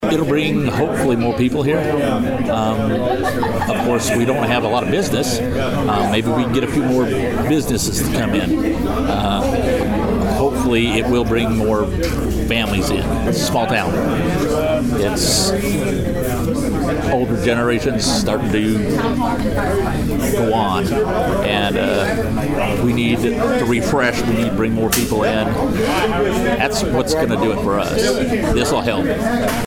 Mayor of Wann Mike Stainbrook discusses the potential economic growth the project brings.